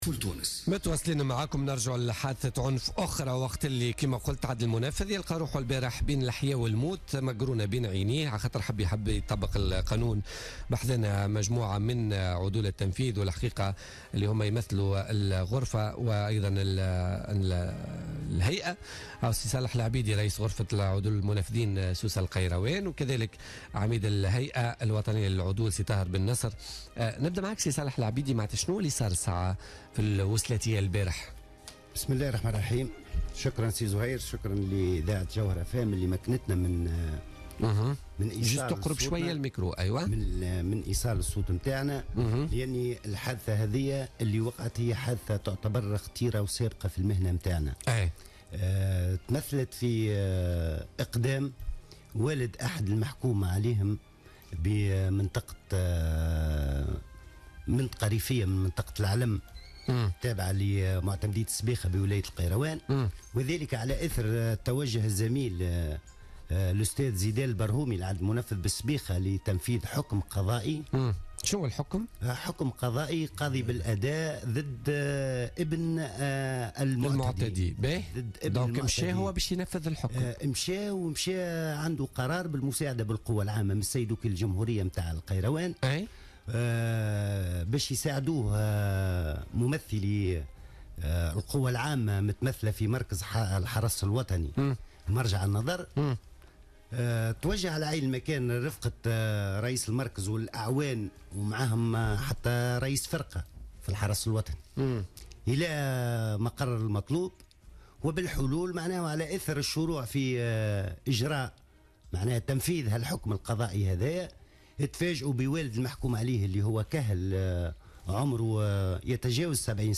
مداخلة في بوليتيكا